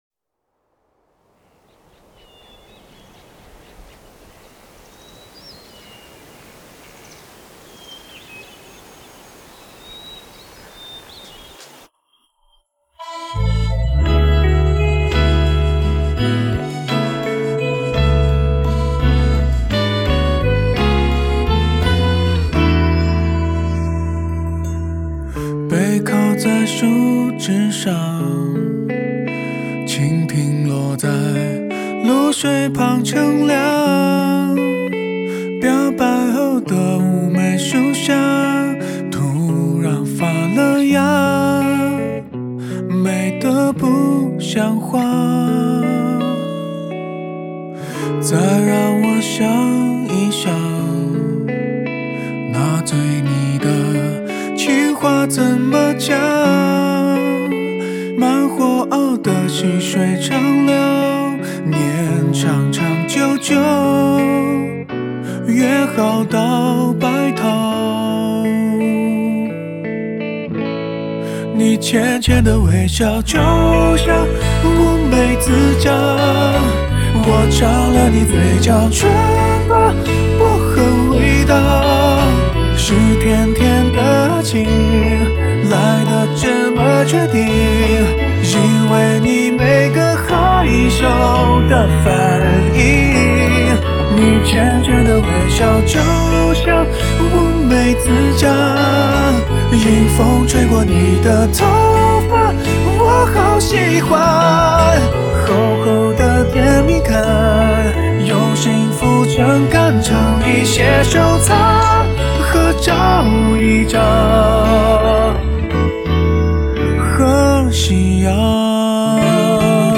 港台